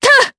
Tanya-Vox_Attack4_jp.wav